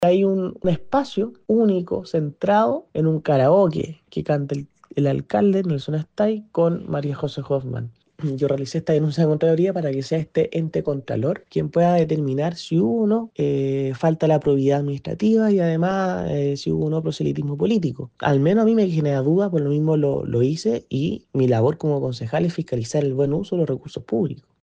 concejal-denuncia-proselitismo-politico-hoffmann.mp3